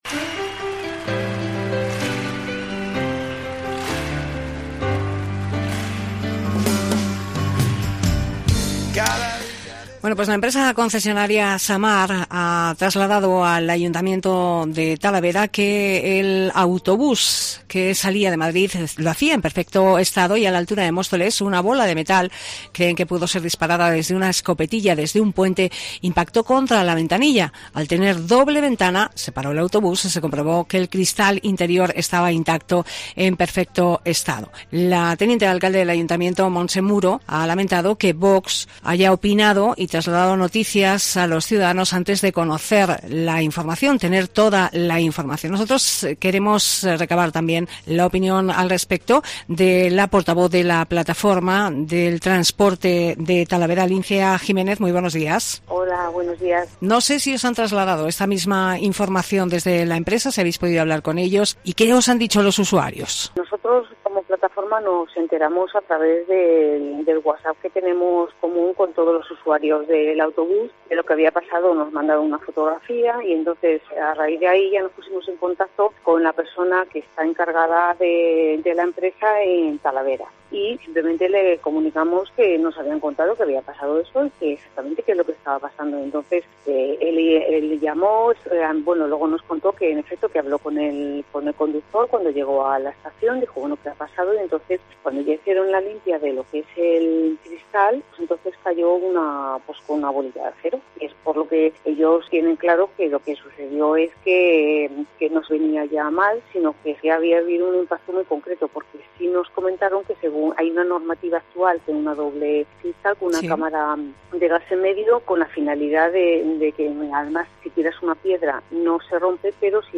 Entrevista Plataforma del Transporte